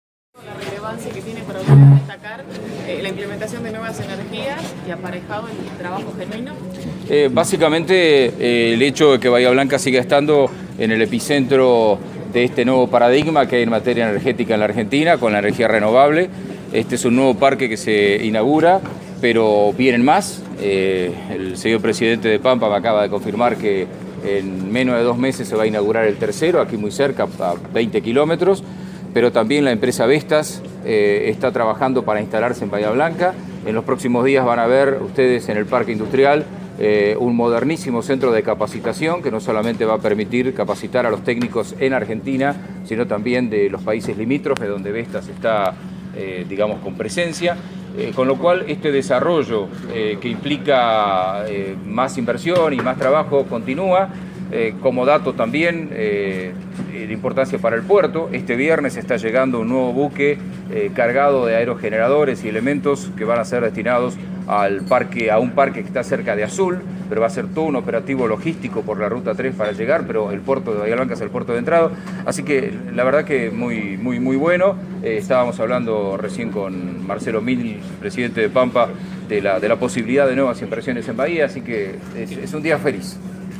El intendente Gay expresó lo siguiente: